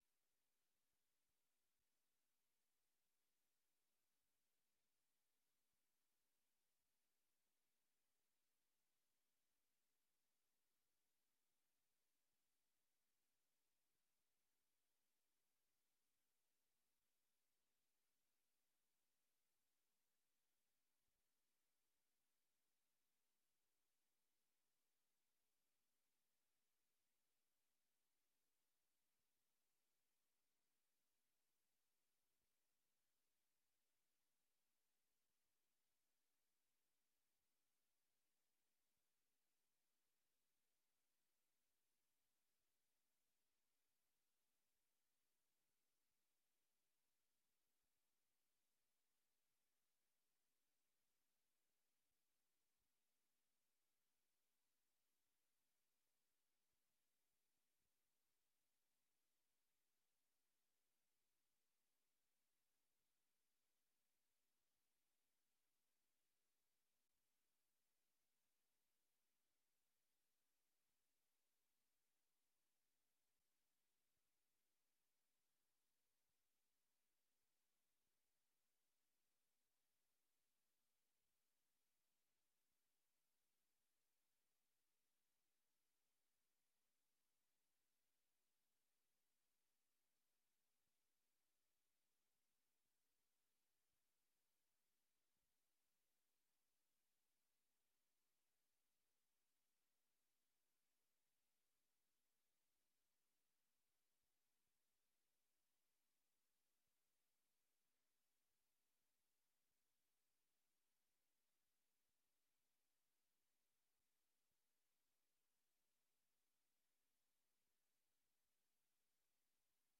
Raadsvergadering 10 maart 2022 18:45:00, Gemeente Dronten
Download de volledige audio van deze vergadering